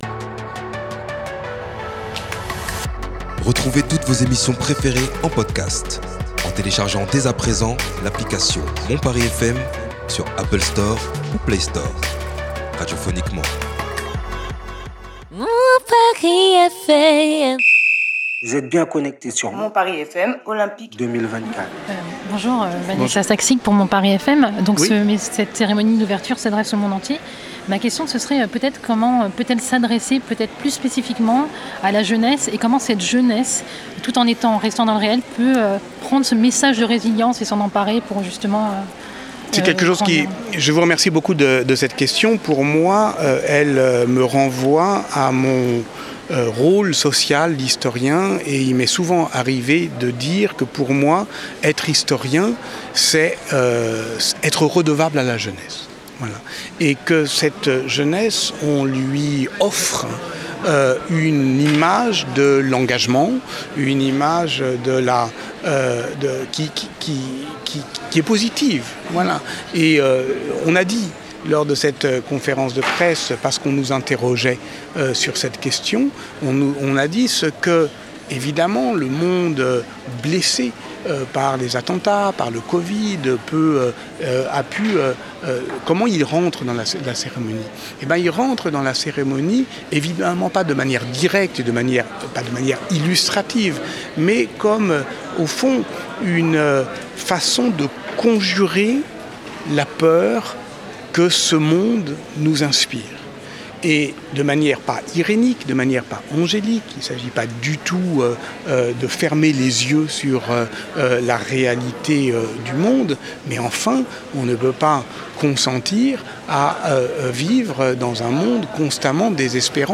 rencontre avec Patrick Boucheron, historien, co-auteur des quatre cérémonies aux côtés de Thomas Jolly.